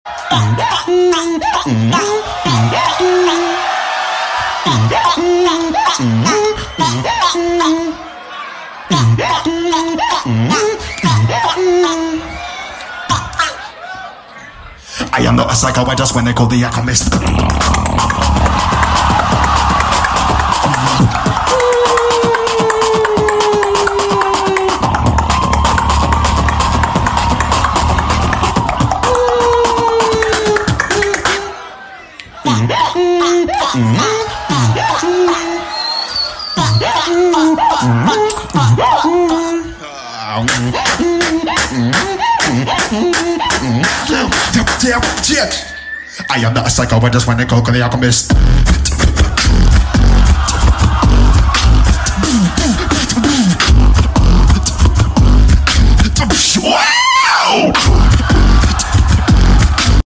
BEATBOXER